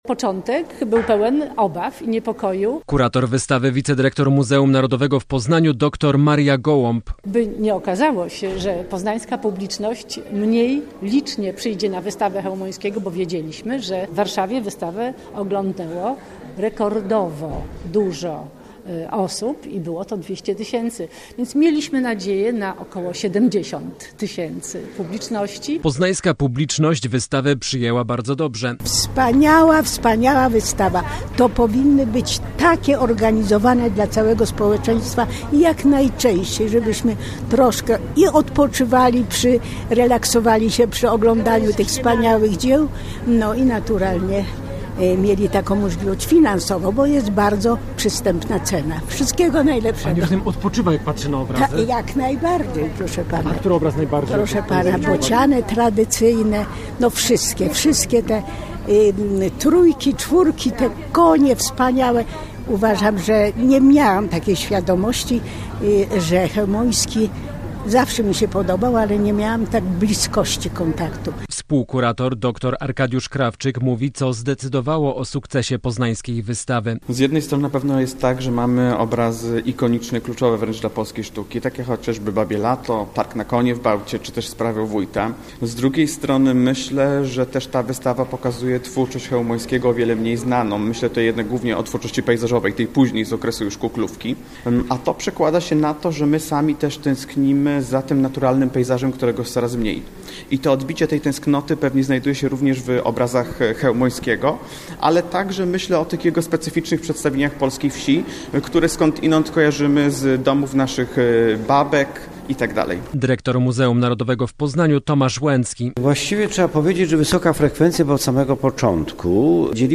SERWIS INFORMACYJNY